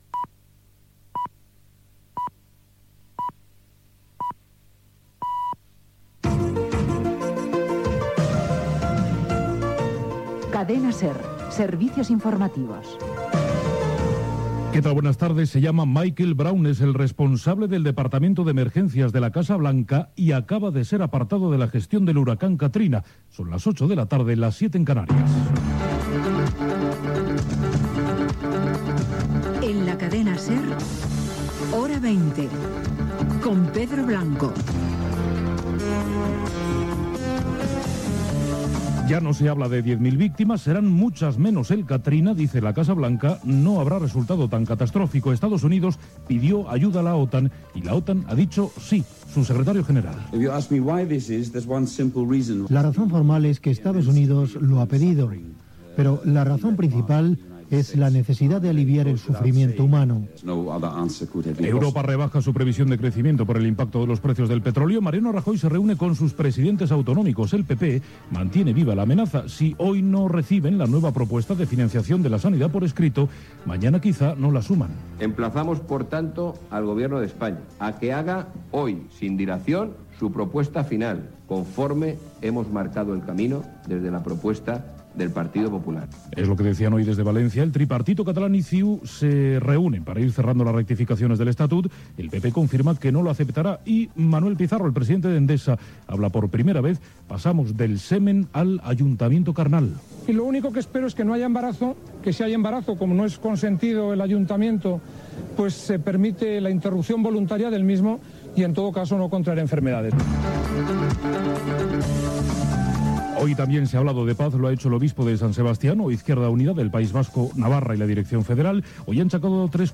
Senyals horaris, salutació, careta del programa i titulars informatius (Huracà Katrina, Partido Popular, etc.).
Informatiu